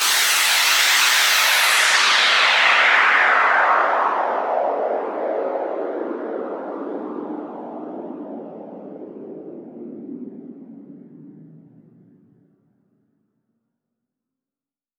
VES2 FX Downlifter
VES2 FX Downlifter 22.wav